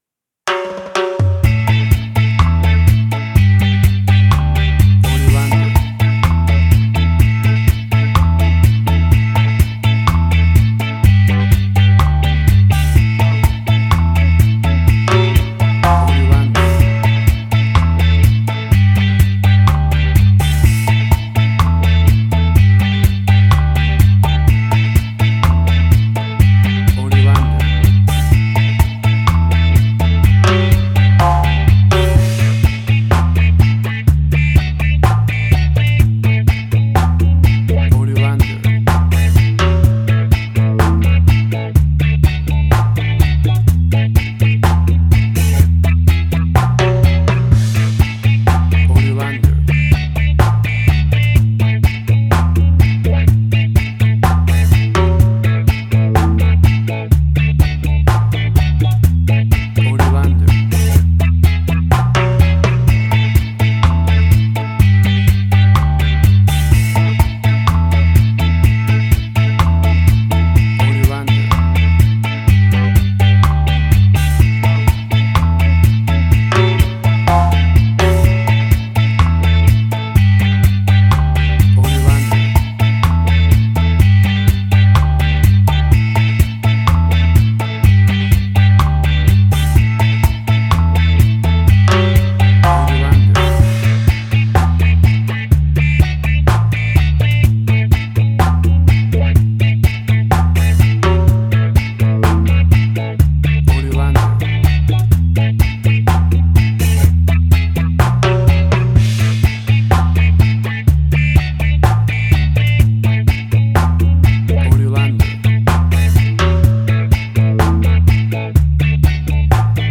Classic reggae music with that skank bounce reggae feeling.
Tempo (BPM): 62